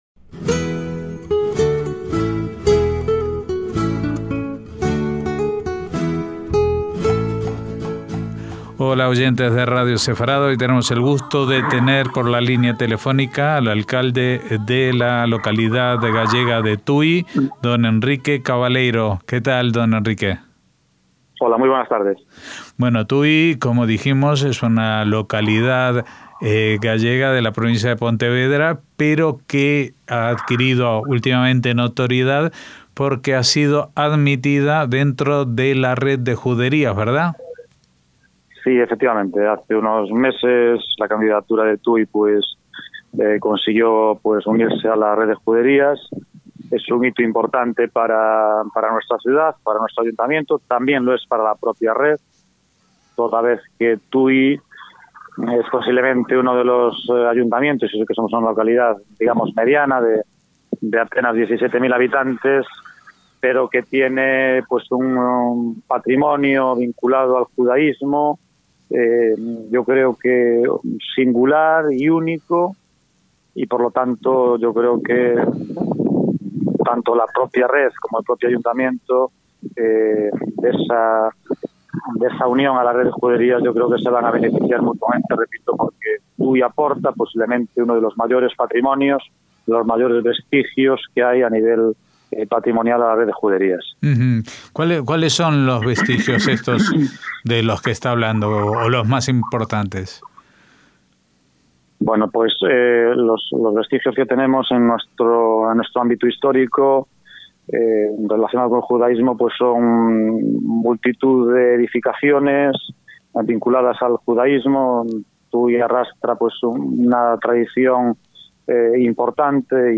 Tui en la Red de Juderías de España, con su alcalde Enrique Cabaleiro